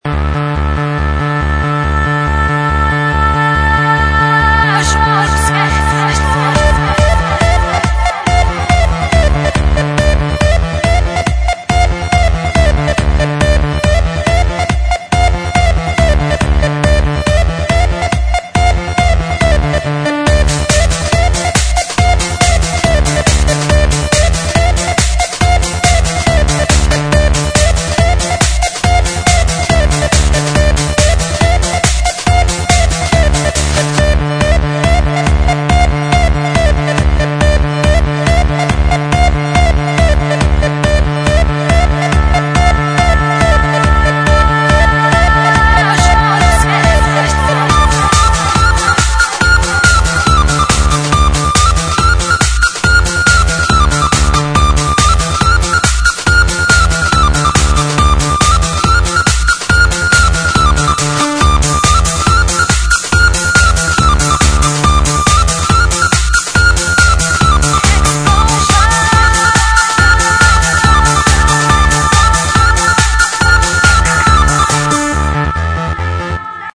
Клубняк